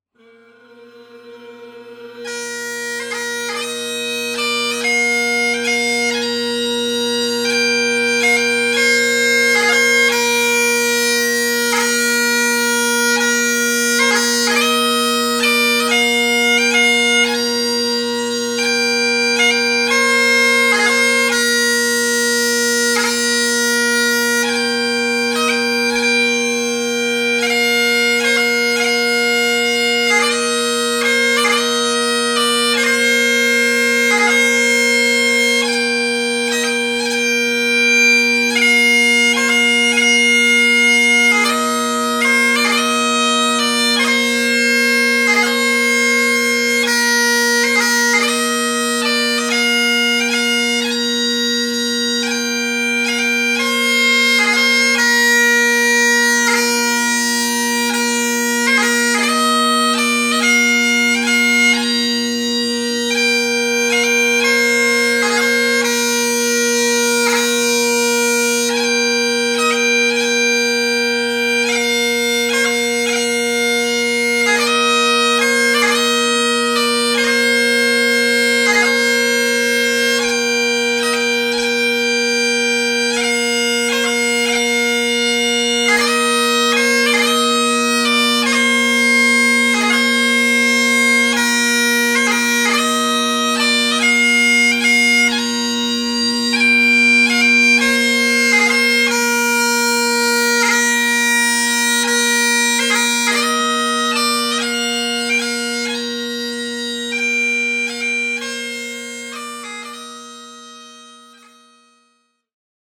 Halifax Bagpiper